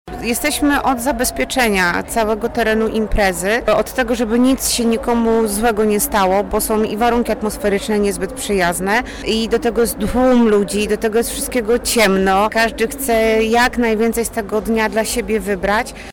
O szczegółach mówi jedna z wolontariuszek Patrolu: